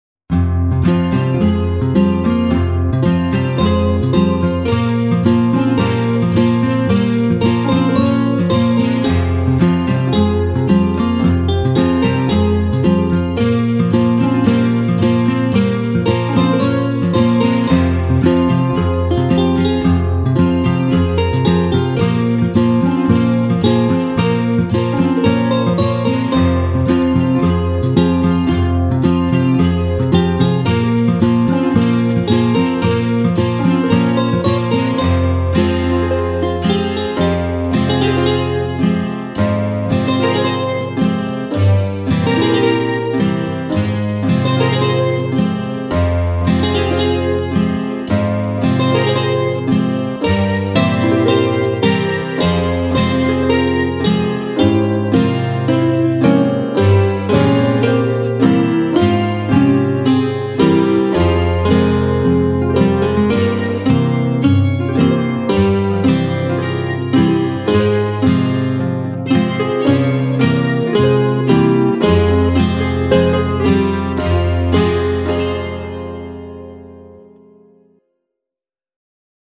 Largo [0-10] - - guitare - harpe - aerien - folk - melodieux
guitare - harpe - aerien - folk - melodieux